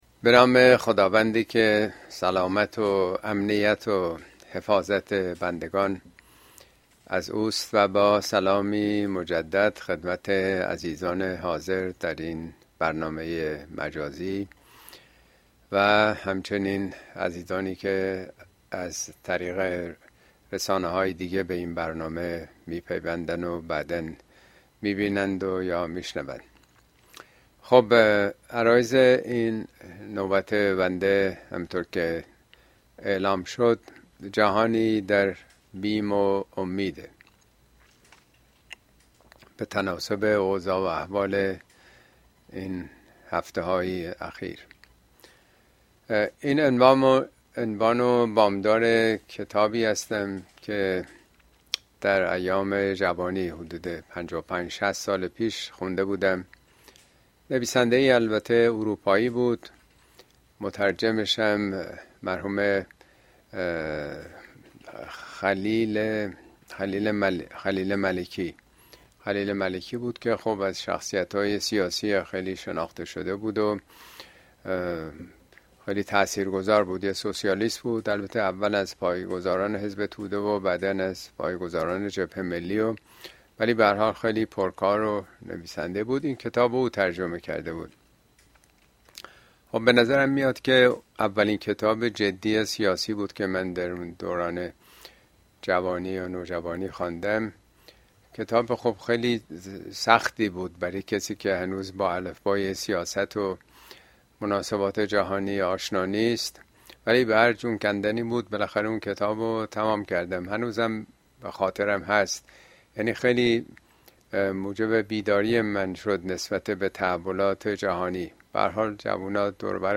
Speech
` موضوعات اجتماعى اسلامى جهانی میان ترس و امید اين سخنرانى به تاريخ ۹ اکتبر ۲۰۲۴ در كلاس آنلاين پخش شده است توصيه ميشود براىاستماع سخنرانى از گزينه STREAM استفاده كنيد.